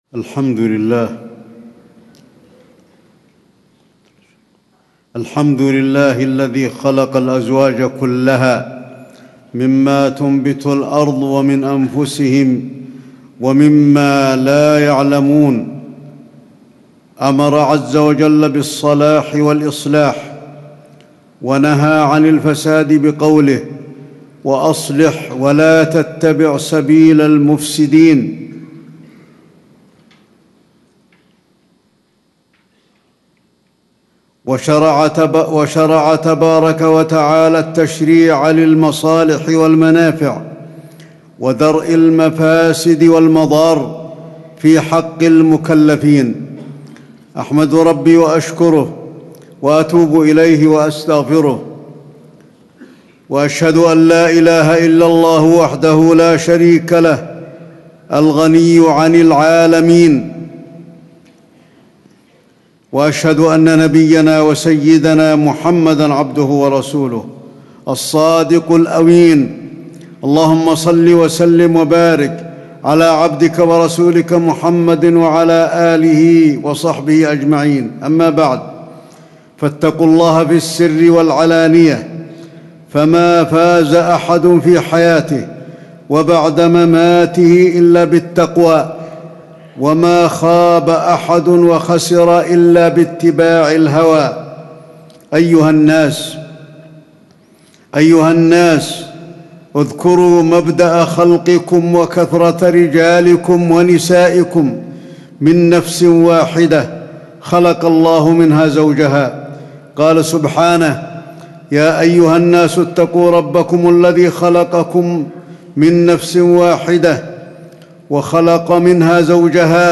تاريخ النشر ٤ ربيع الثاني ١٤٣٩ هـ المكان: المسجد النبوي الشيخ: فضيلة الشيخ د. علي بن عبدالرحمن الحذيفي فضيلة الشيخ د. علي بن عبدالرحمن الحذيفي إصلاح البيوت وخطورة الطلاق